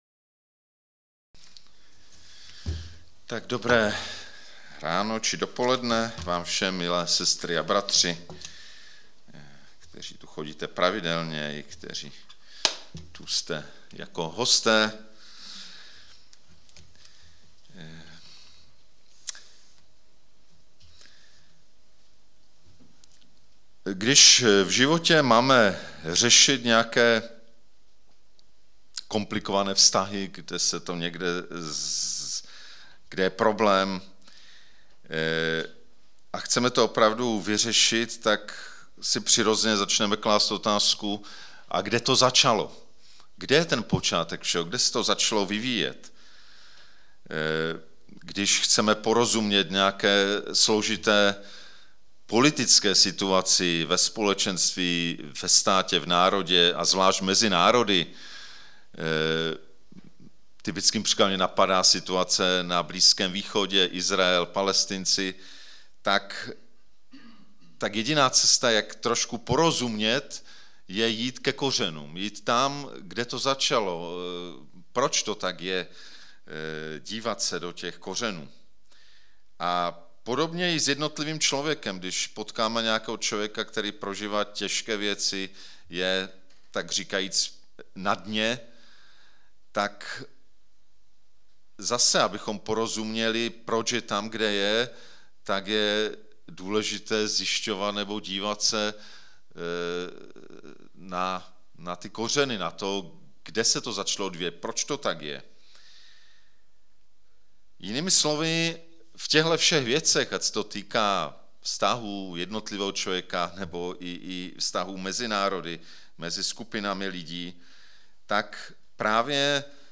1. díl ze série kázání "Ve světle počátku", Gn 1,1-5
Kategorie: nedělní bohoslužby